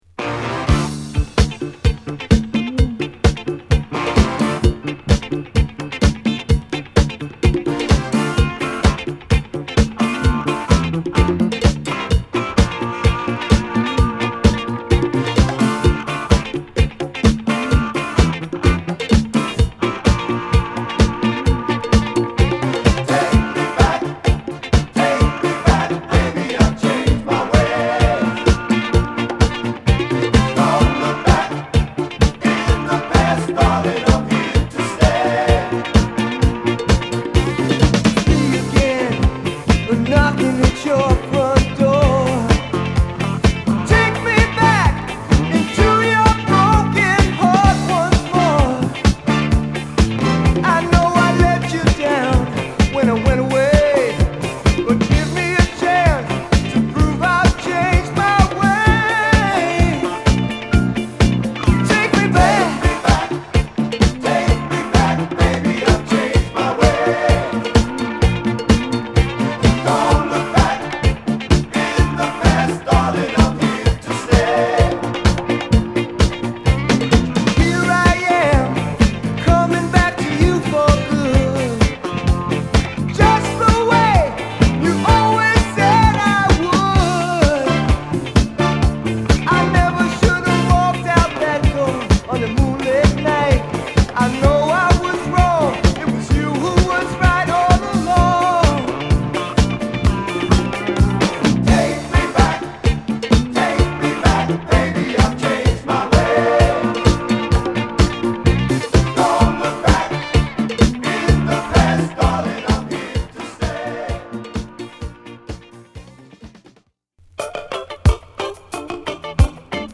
ブルックリン出身のヴォーカル・グループ